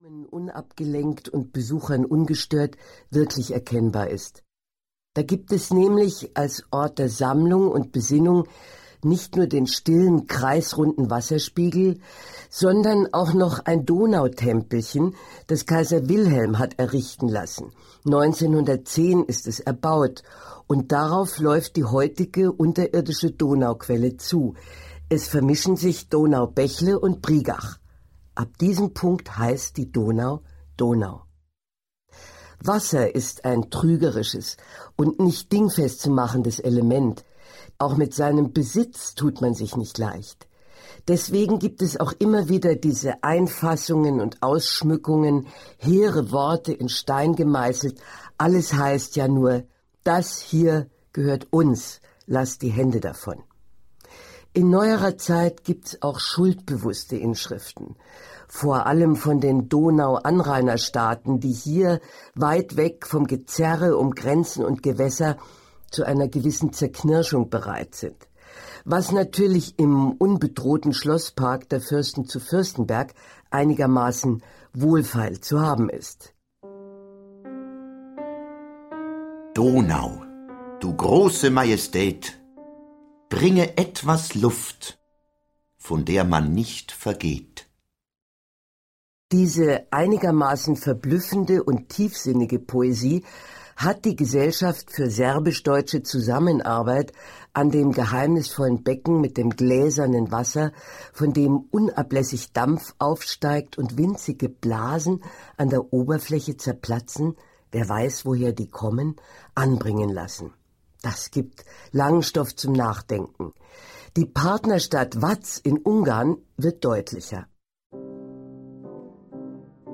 Mama Donau - Eva Demski - Hörbuch
Hörbuch Mama Donau, Eva Demski.